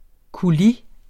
Udtale [ kuˈli ]